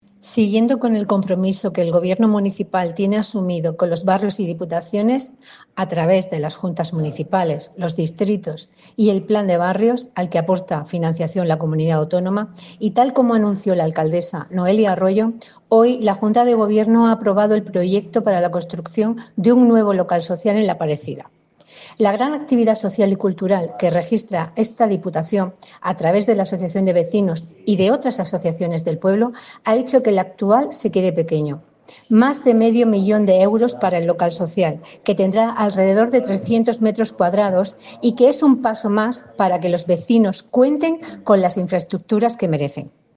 Enlace a Declaraciones de Francisca Martínez Sotomayor, concejal de Descentralización